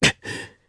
Riheet-Vox_Damage_jp_02.wav